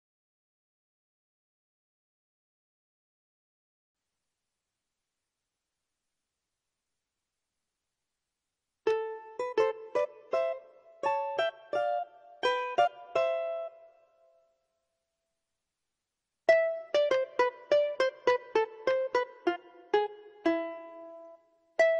Домра — старинный русский инструмент с нежным и звонким звучанием.
Набор звуков на домре – русский народный щипковый инструмент